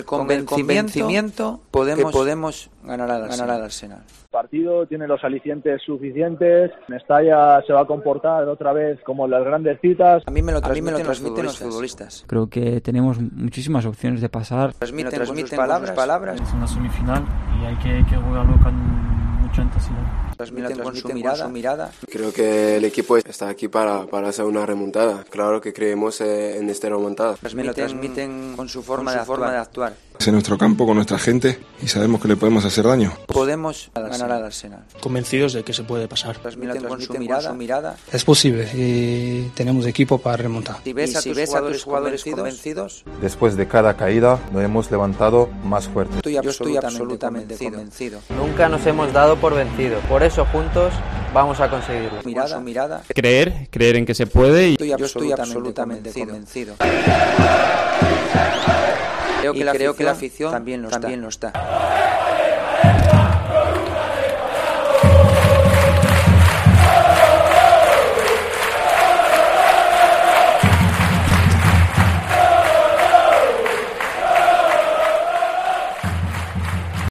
Montaje sonoro de los futbolistas del Valencia CF, que apuestan por la remontada en semifinales ante el Arsenal de Unai Emery (3-1).
Marcelino, Parejo, Rodrigo, Gameiro, Coquelin, Garay, Soler, Paulista, Kondogbia, Cheryshev y Gayá son los protagonistas de este montaje sonoro que nos conduce a un día que puede marcar la historia del equipo de Mestalla.